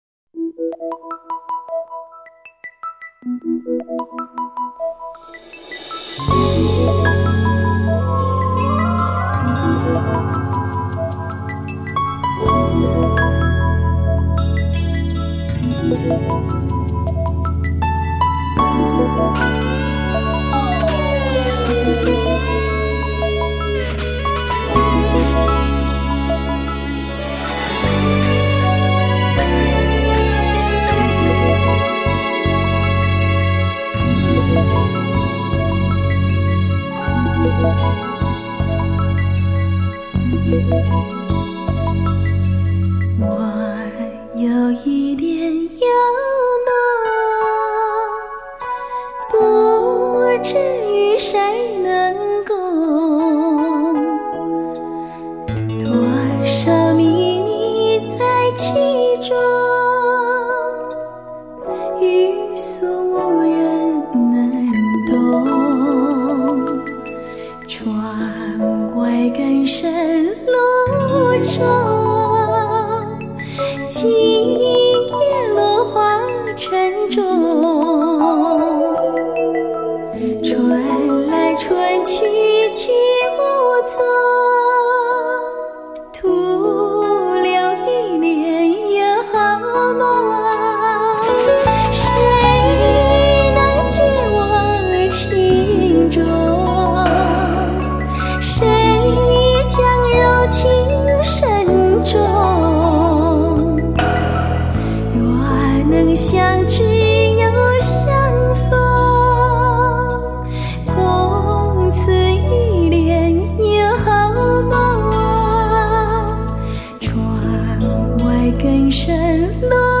70年代电影金曲 文艺电影 绕梁名曲
24BIT数码高频 原音毕露